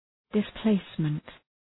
Shkrimi fonetik {dıs’pleısmənt}